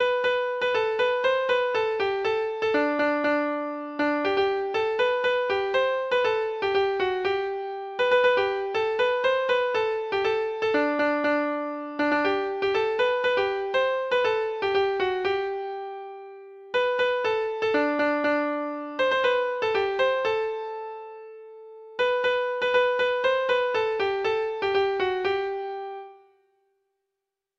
Folk Songs from 'Digital Tradition' Letter Y Your Daughters and Your Sons
Free Sheet music for Treble Clef Instrument